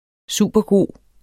Udtale [ ˈsuˀbʌˈgoˀ ]